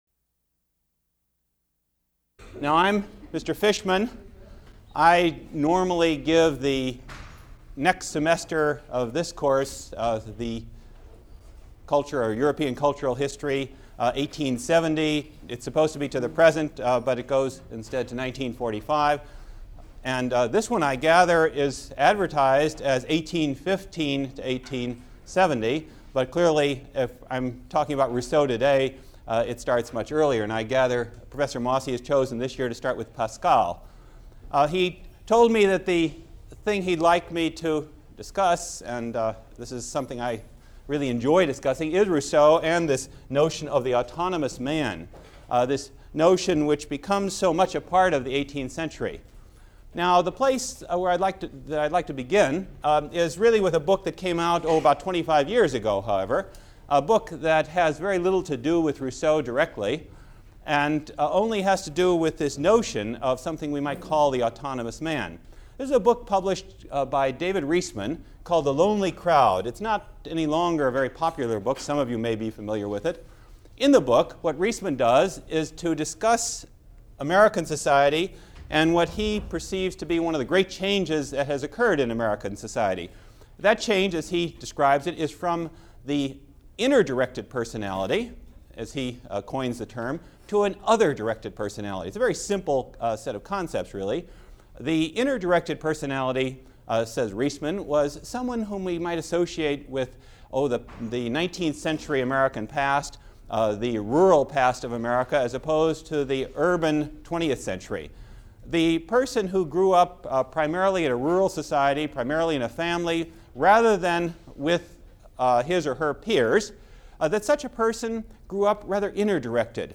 Mosse Lecture #6